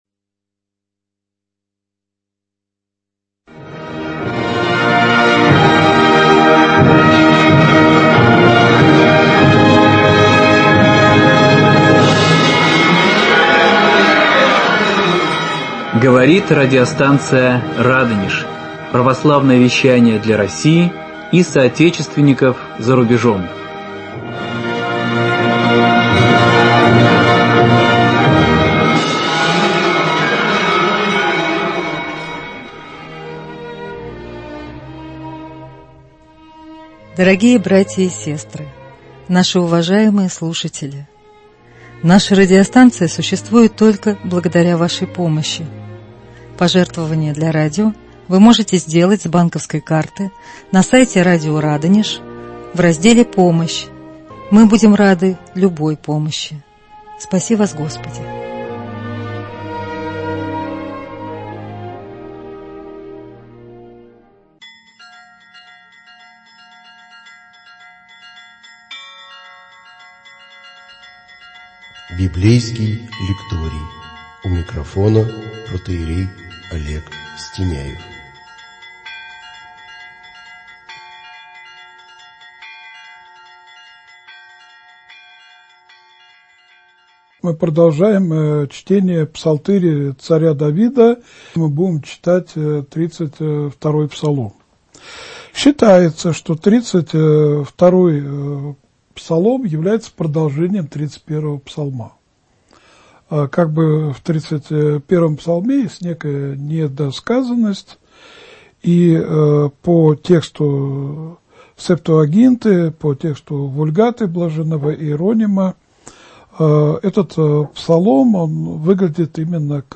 цикл бесед на избранные псалмы царя Давида. Псалом 32, 33, 35